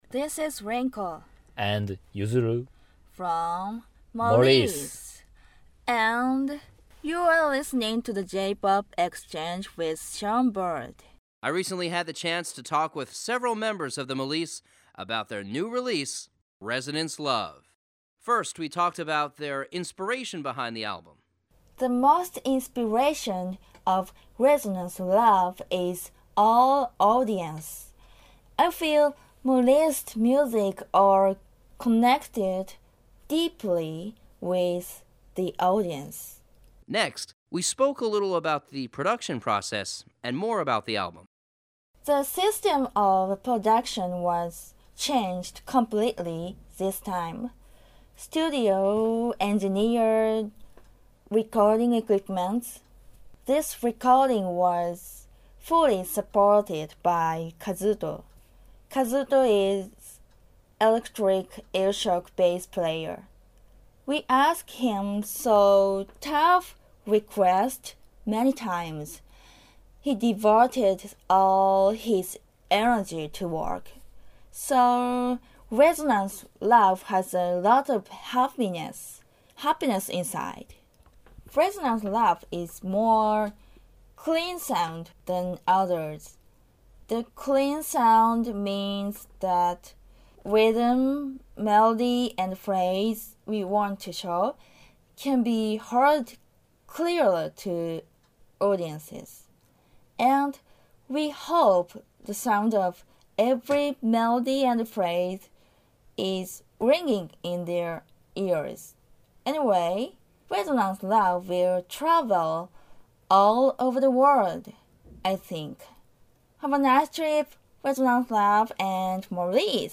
Conversation & Questions:
Molice_2014_Conversation.mp3